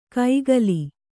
♪ kaigali